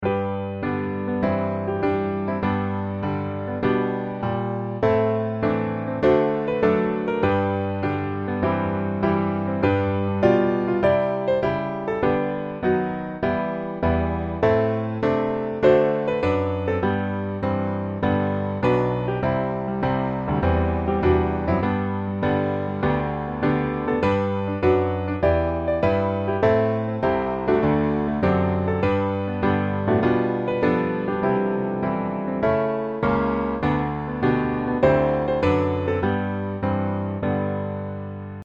G Major